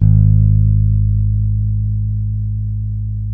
-MM JAZZ G#2.wav